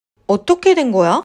되다の発音は、正確には「トゥェダ」ですが、「テダ」に近い感じで聞こえると思います。